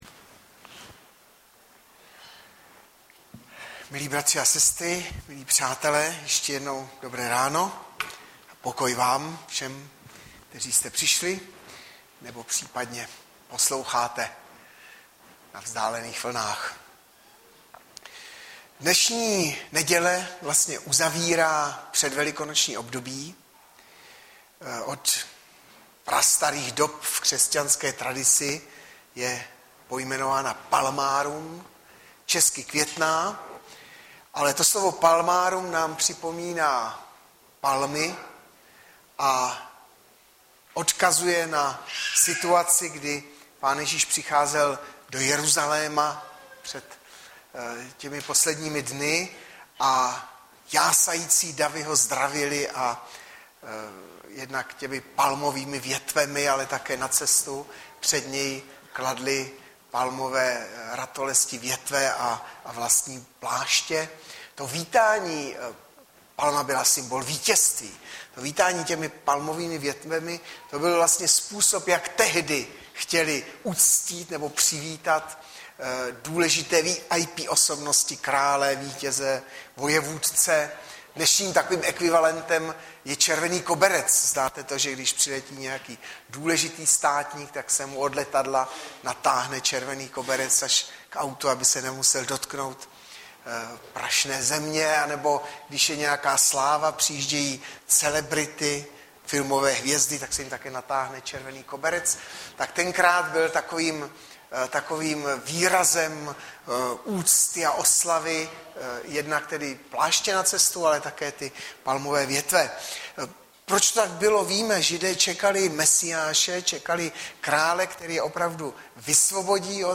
Hlavní nabídka Kázání Chvály Kalendář Knihovna Kontakt Pro přihlášené O nás Partneři Zpravodaj Přihlásit se Zavřít Jméno Heslo Pamatuj si mě  24.03.2013 - ZEMŘI, ABYS ŽIL - Jan 12,12-26 Audiozáznam kázání si můžete také uložit do PC na tomto odkazu.